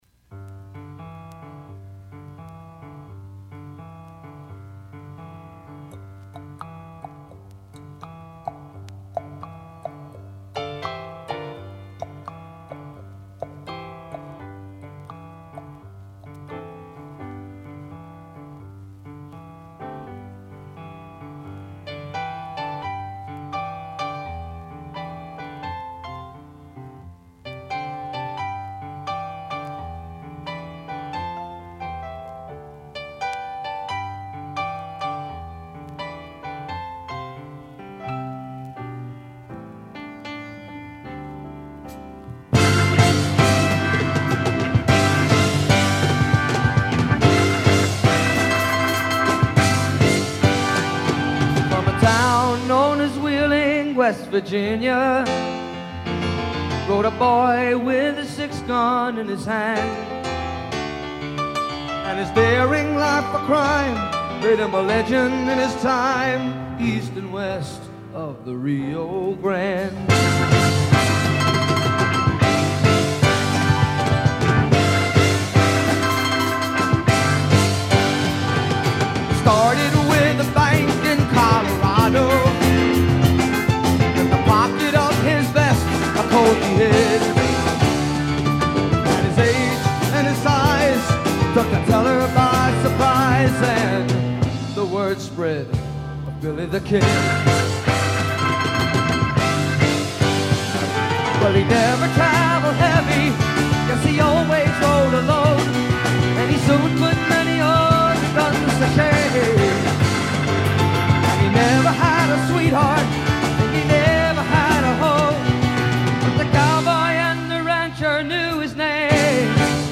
a very rare live version which we share here.